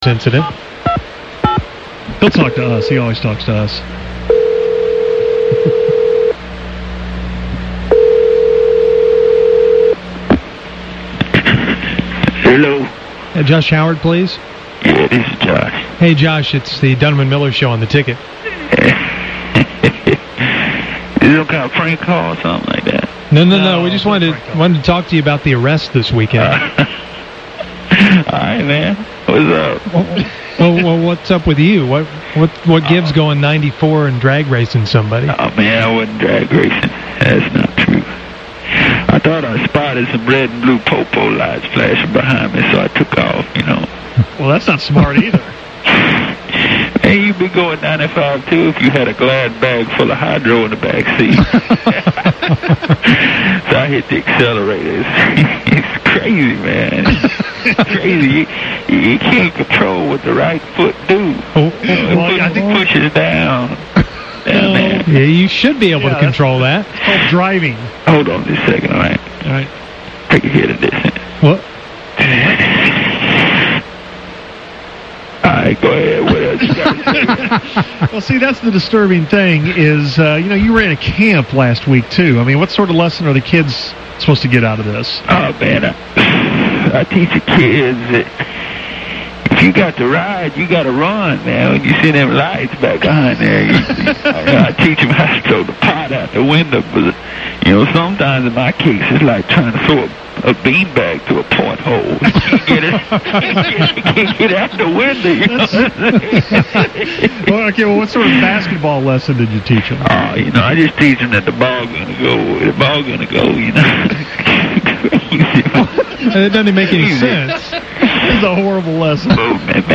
Fake Josh called into the Musers to talk about it.
fake-josh-howard-racing.mp3